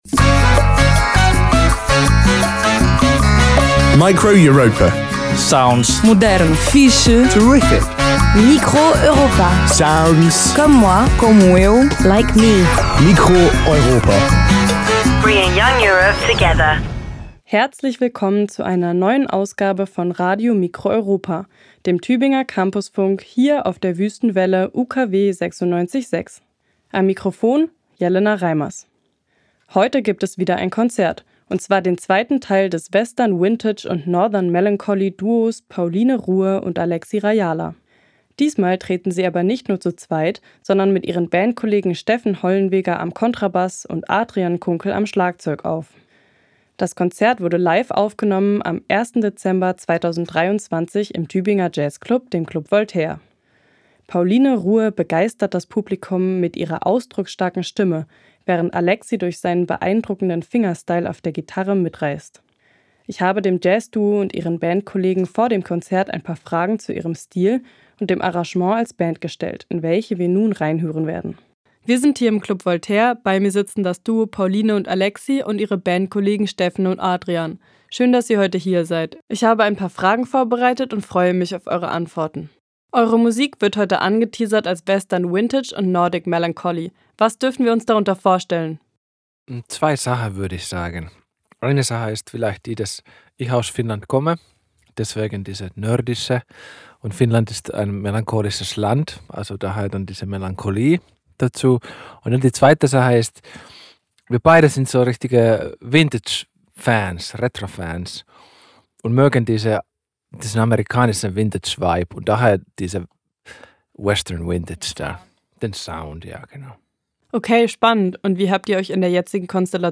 Western Vintage & Northern Melancholy
Kontrabass
Schlagzeug
live aufgenommen am 1. Dezember 2023 im Tübinger Jazzclub
Gitarre
Das Jazzduo mit Band
Gesang
Form: Live-Aufzeichnung, geschnitten